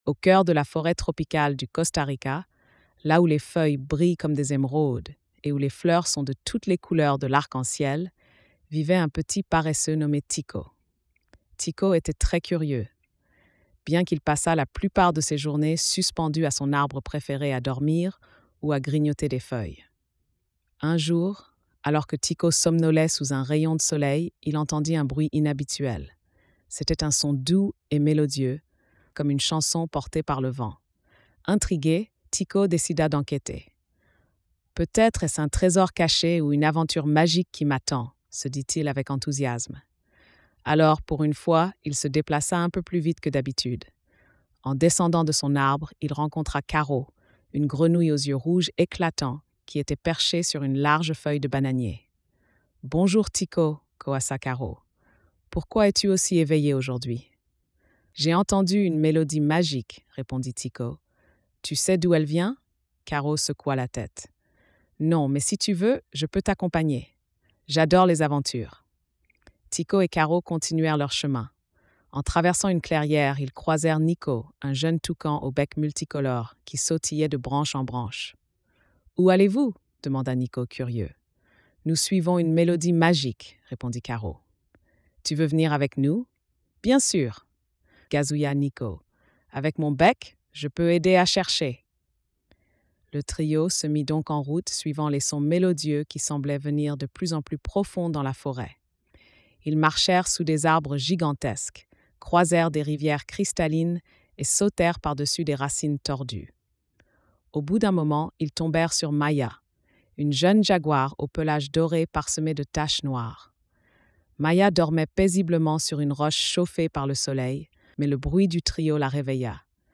Le Secret de la Forêt Magique - Conte de fées en français. Texte et Audio.
🎧 Lecture audio générée par IA